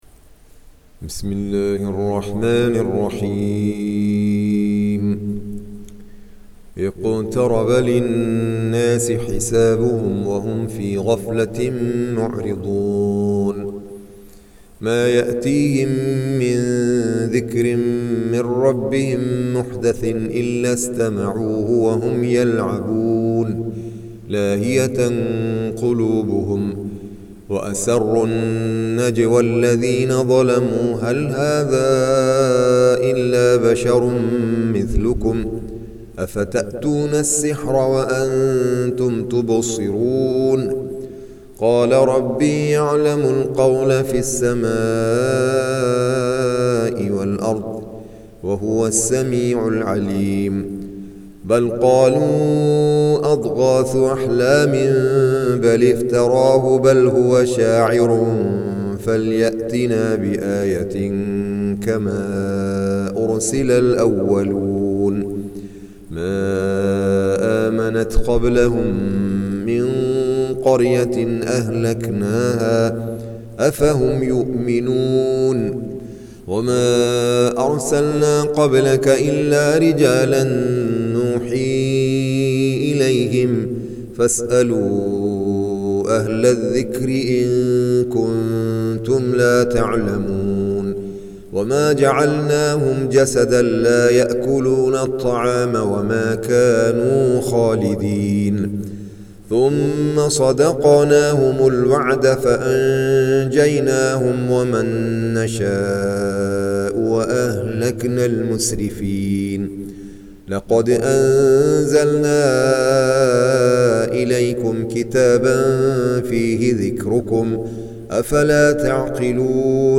Surah Repeating تكرار السورة Download Surah حمّل السورة Reciting Murattalah Audio for 21. Surah Al-Anbiy�' سورة الأنبياء N.B *Surah Includes Al-Basmalah Reciters Sequents تتابع التلاوات Reciters Repeats تكرار التلاوات